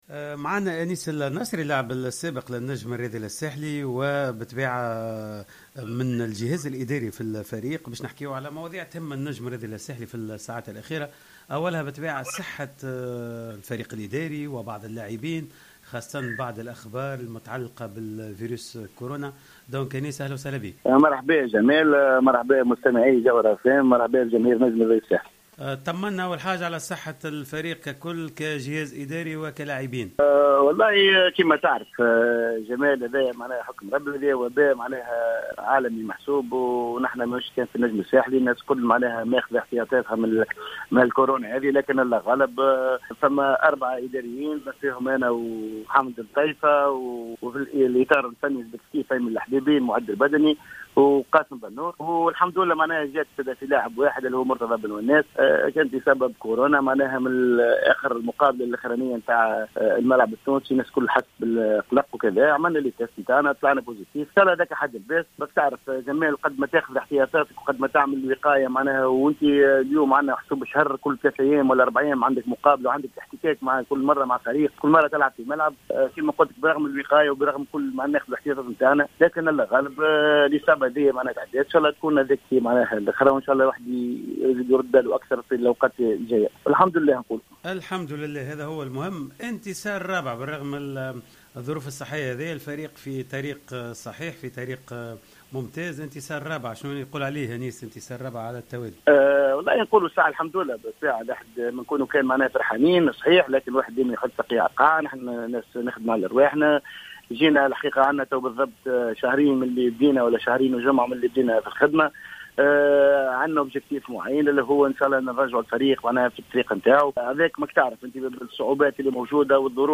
الحوار الكامل